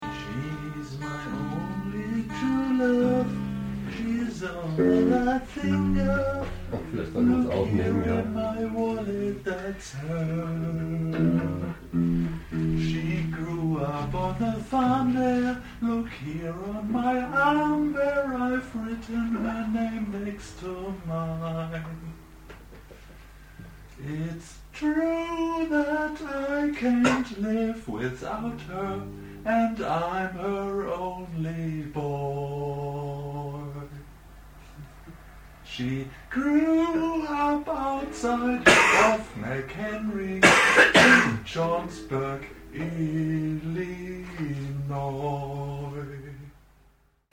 in einer Probepause